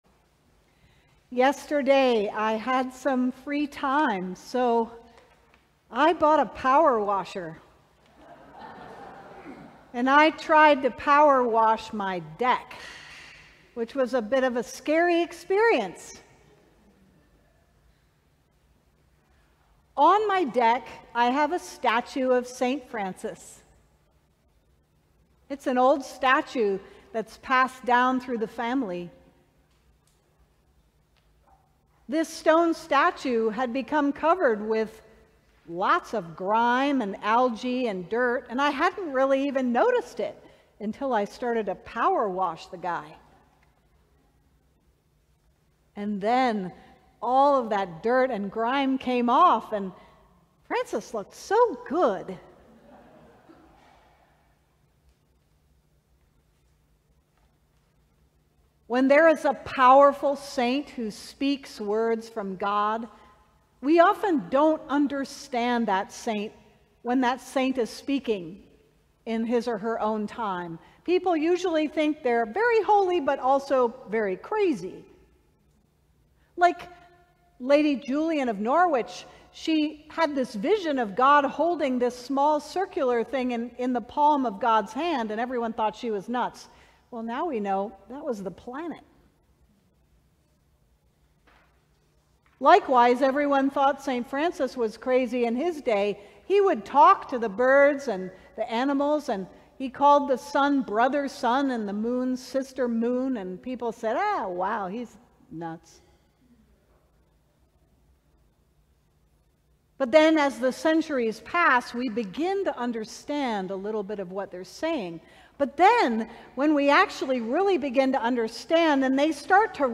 Sermon: Power Washing Francis - St. John's Cathedral